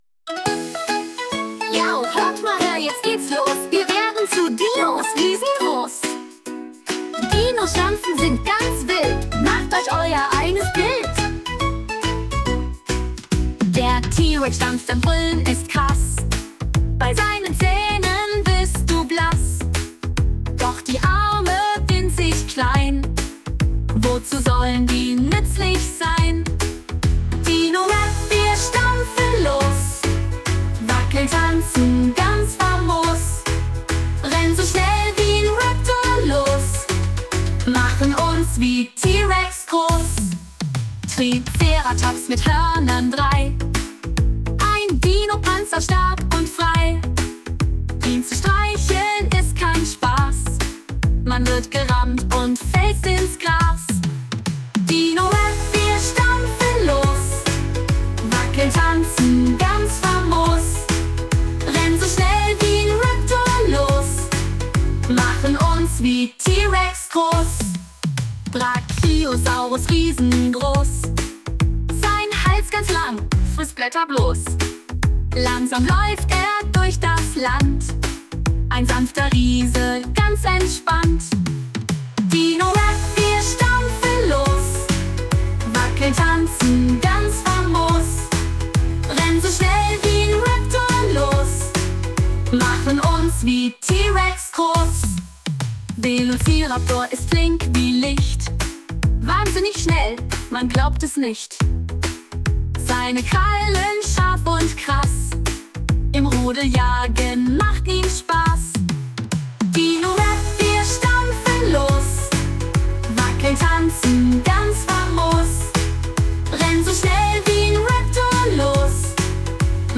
Zum Beispiel so wir der folgende Dino-Rap, dessen Text mit Hilfe von KI erstellt wurde und danach ein Song daraus komponiert.